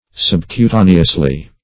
Sub`cu*ta"ne*ous*ly, adv.